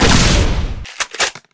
Holorifleshot&chamber.ogg